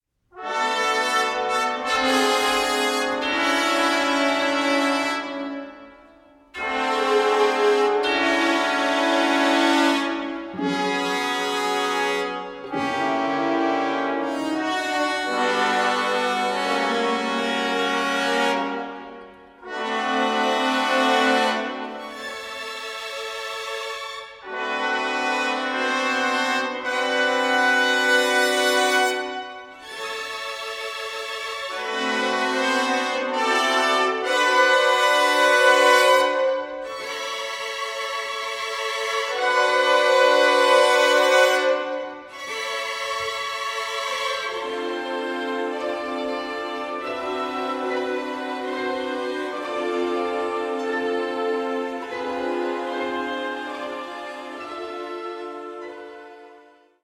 (live recording)